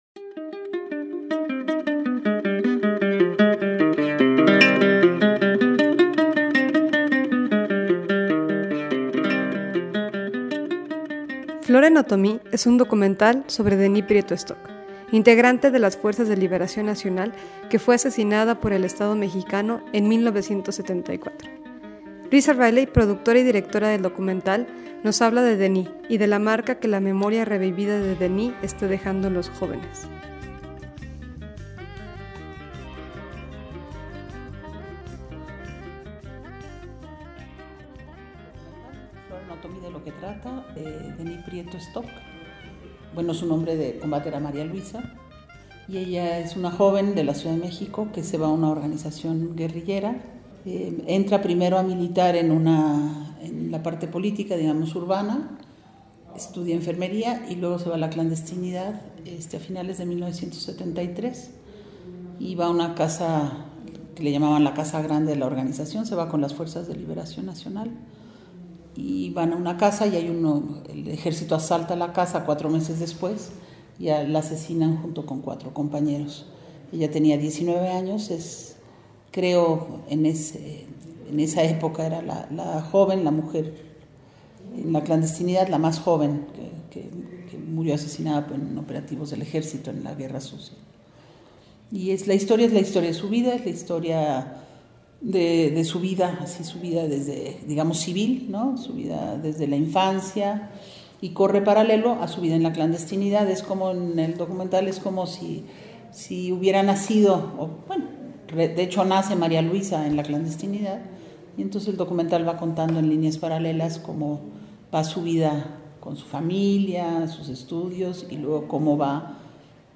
Versión corta de la entrevista (10 min):